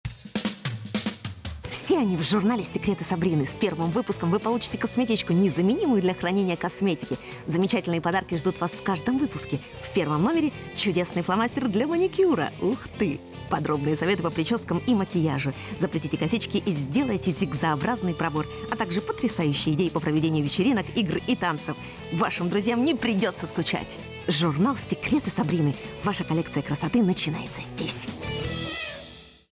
RUSSIAN. Experienced actress and voice artist.
She has since worked extensively in Russian and English, where her smokey Eastern European voice has been used in many films, TV and radio programs, corporate videos and commercials (of course for vodka!).